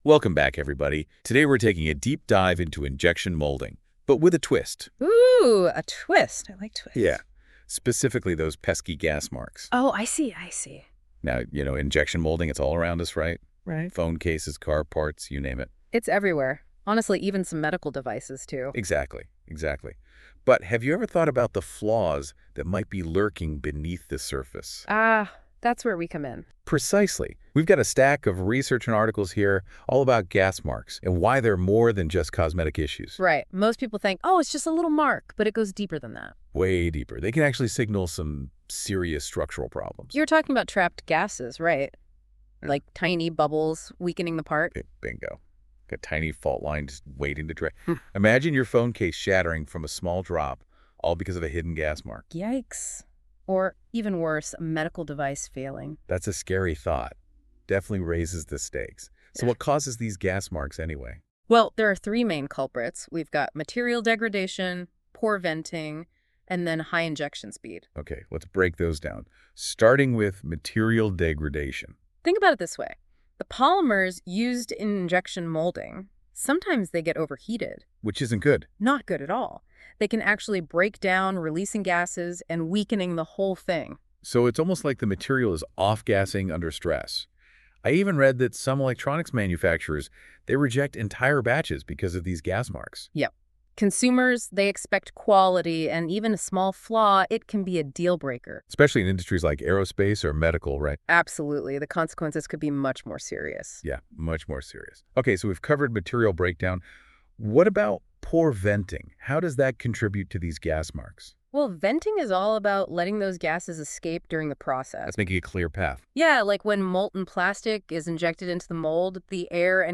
Podcast – How Do Gas Marks Affect the Injection Molding Process?